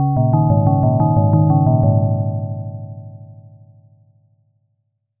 These were created with SuperCollider.
The faster the music is the less time you have left.